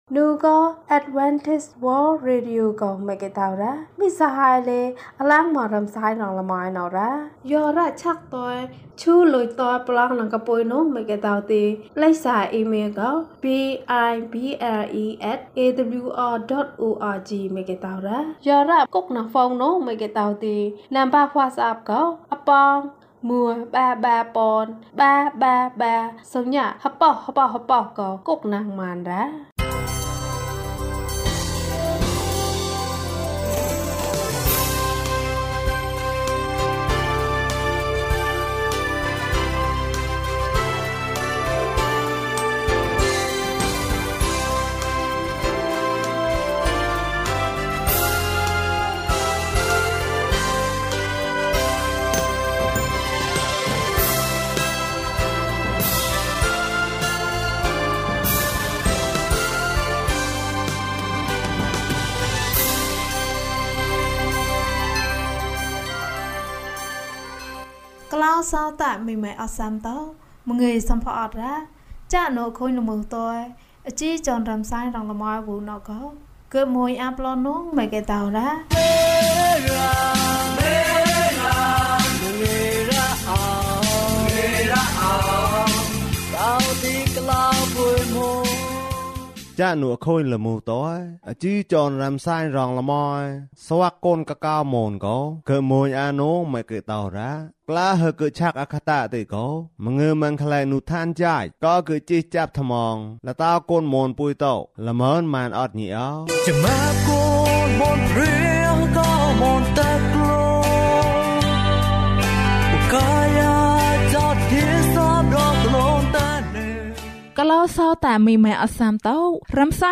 အံ့သြဖွယ်ဘုရားသခင်။၀၁ ကျန်းမာခြင်းအကြောင်းအရာ။ ဓမ္မသီချင်း။ တရားဒေသနာ။